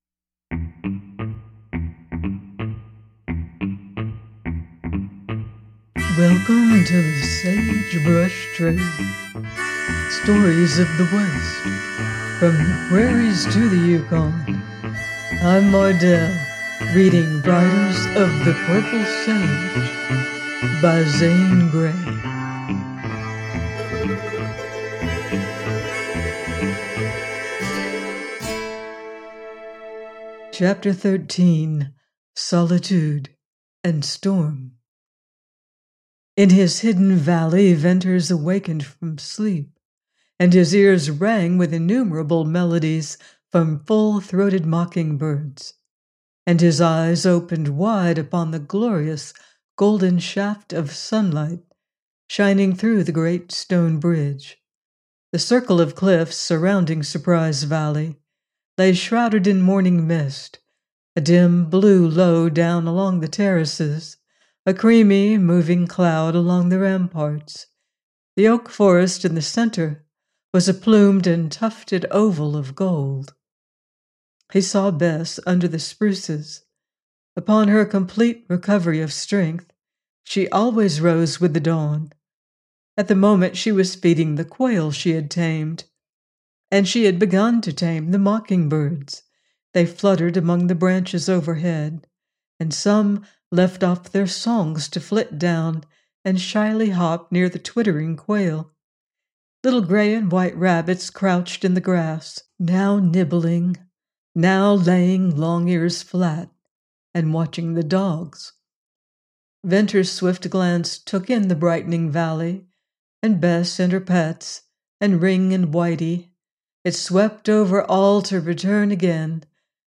Riders Of The Purple Sage – Ch. 13: by Zane Grey - audiobook